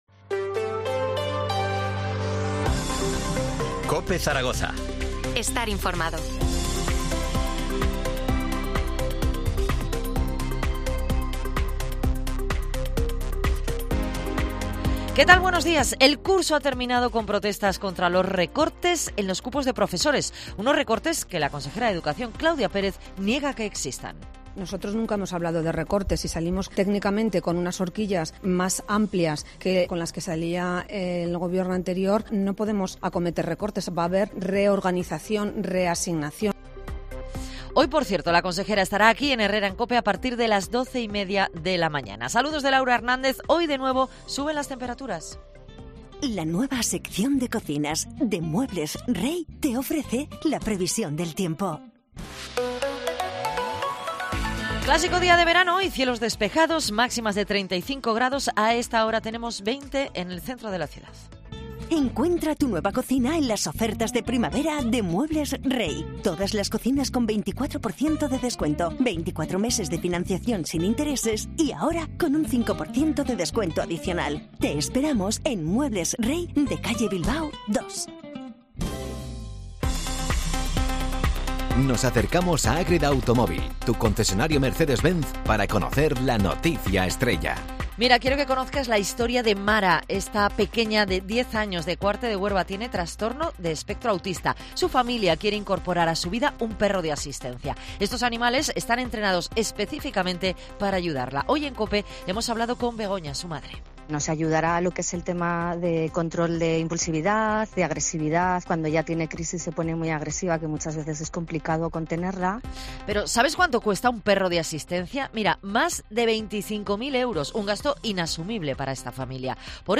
Titulares del día en COPE Zaragoza